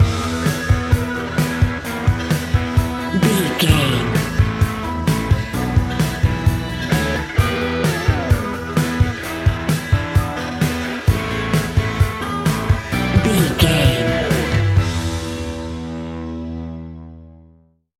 Ionian/Major
E♭
hard rock
blues rock
distortion
instrumentals